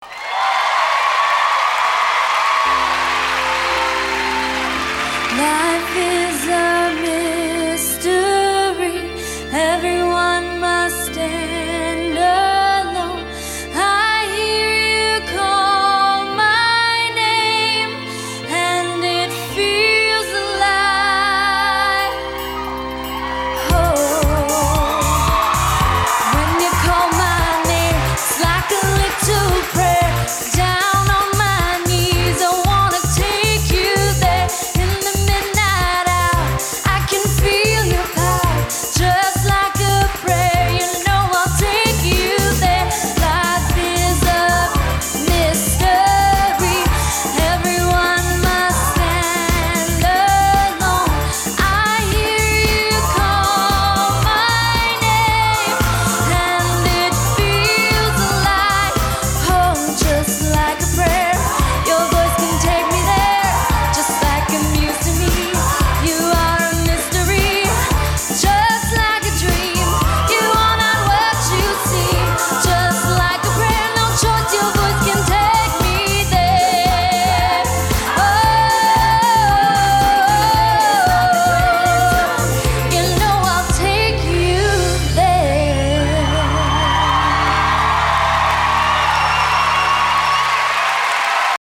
They should now  be higher quality and have the same volume.